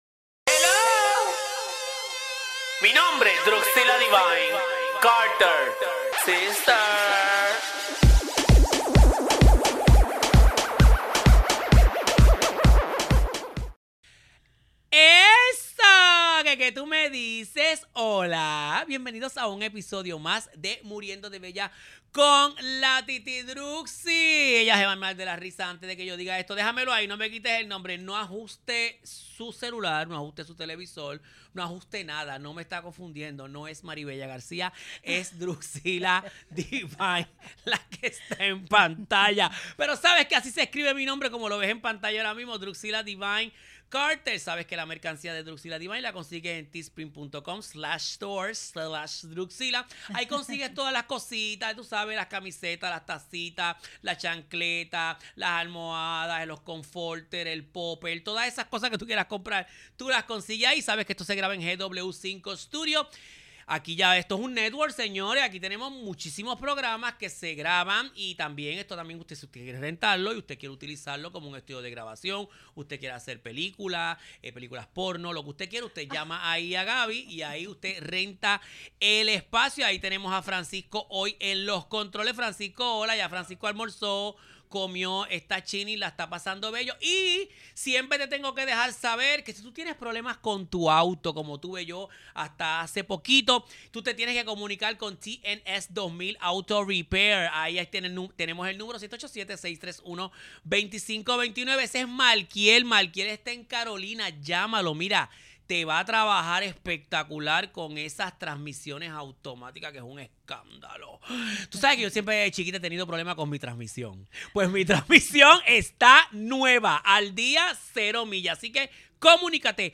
Grabado en los estudios de GW-Cinco y somos parte del GW5 Network.